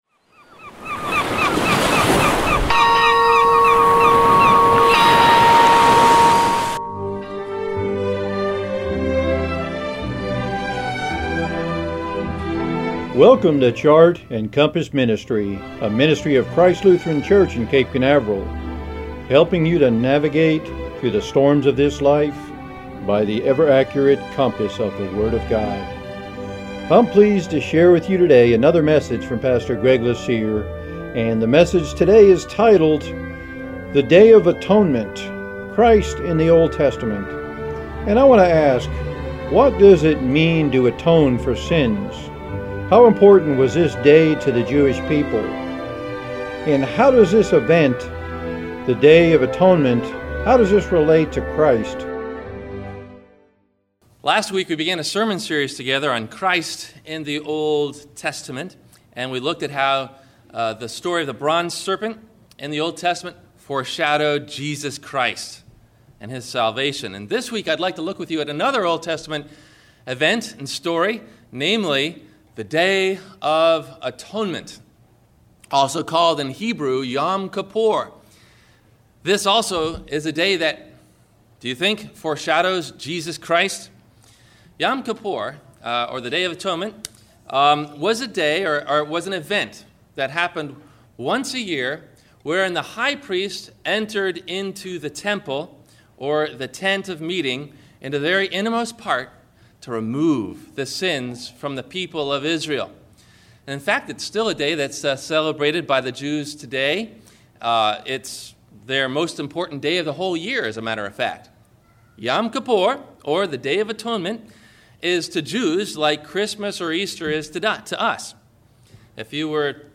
Have a Comment or Question about the Sermon?